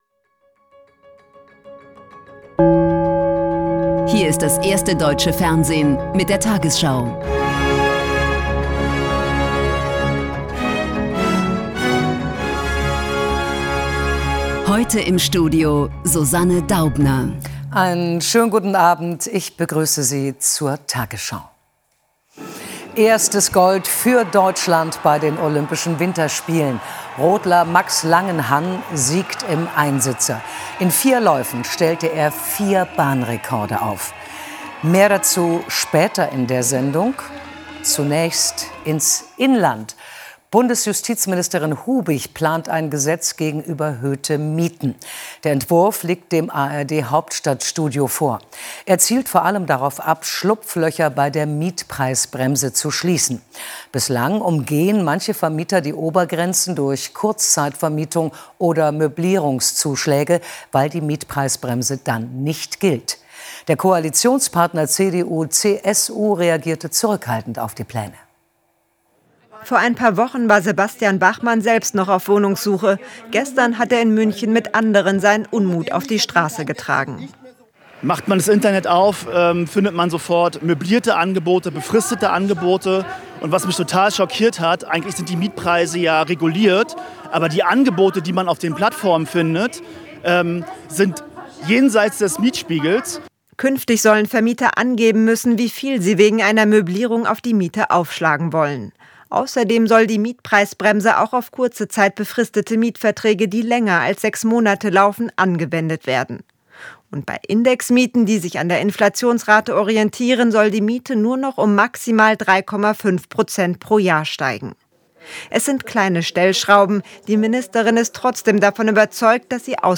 tagesschau 20:00 Uhr, 08.02.2026 ~ tagesschau: Die 20 Uhr Nachrichten (Audio) Podcast